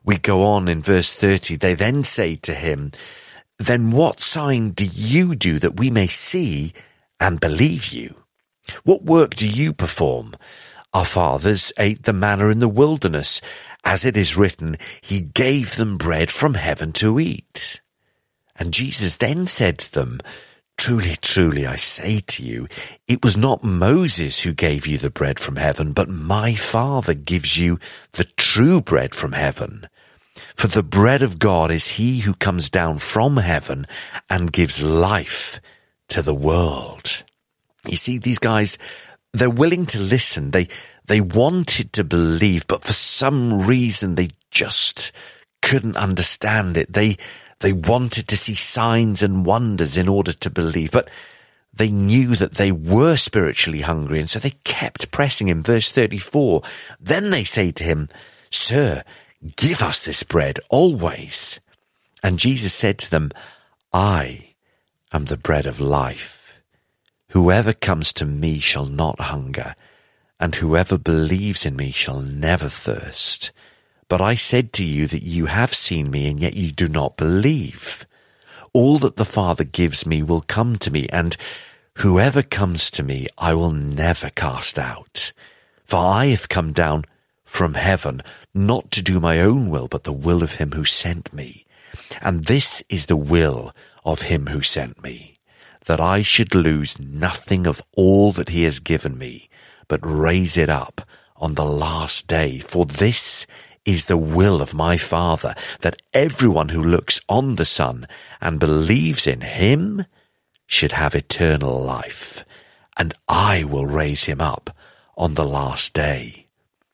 teachers on the daily Bible audio commentary